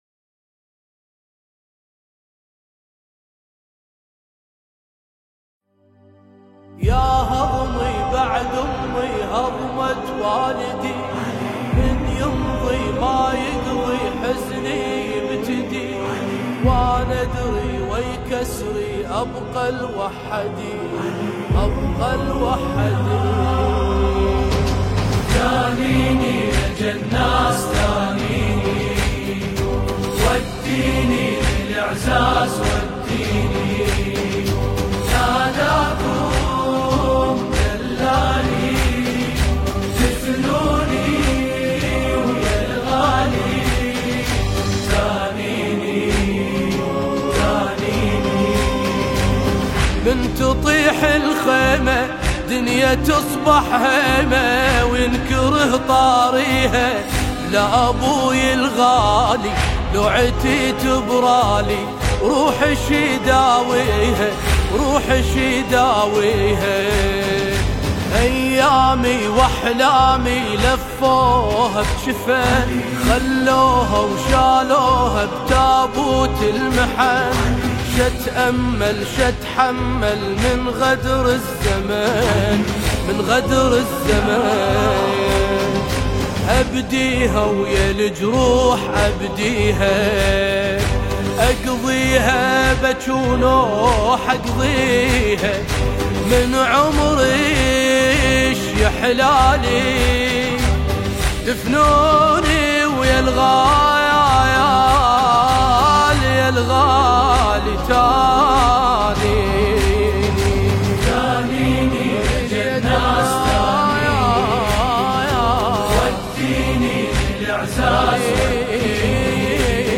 اداء
الكورال